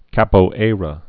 (kăpō-ārə, käp-ārä)